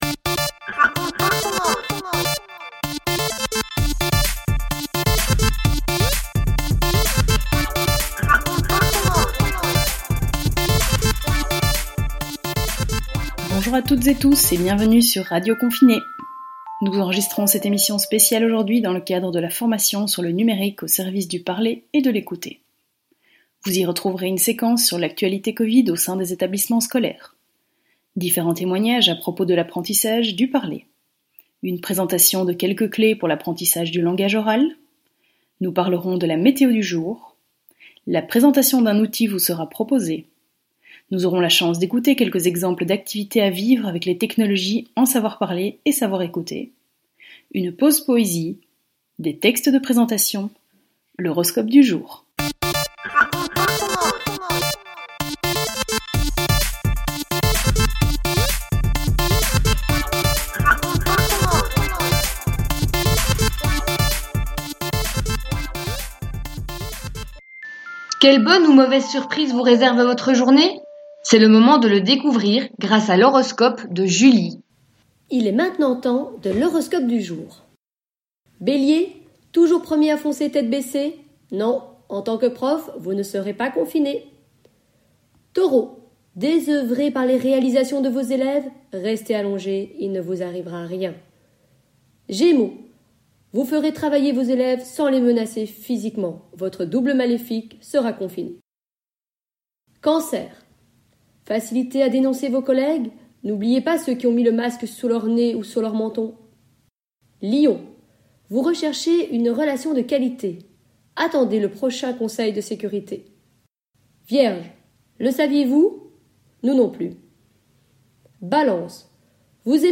Comme promis, voici votre émission radio.